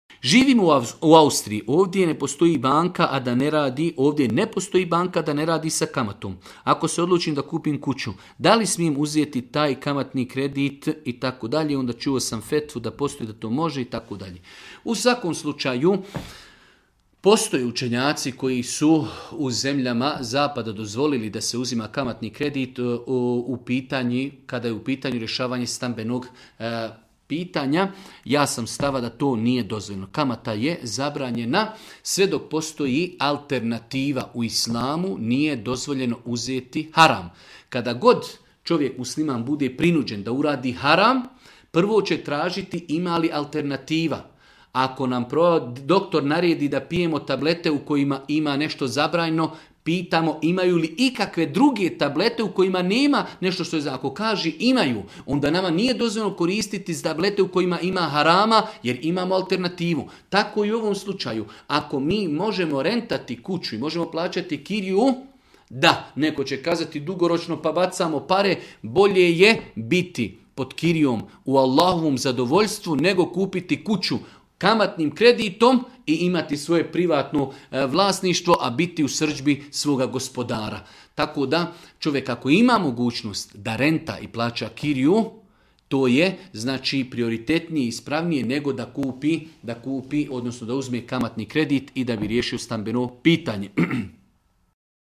video predavanju ispod.